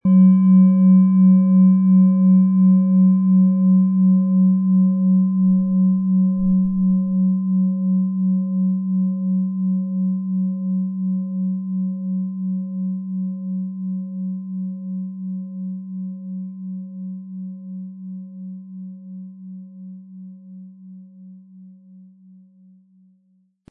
OM Ton
• Tiefster Ton: Mond
HerstellungIn Handarbeit getrieben
MaterialBronze